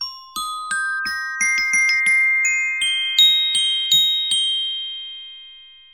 tonal layer           glockenspiel: tonal layer
Glock_ton.wav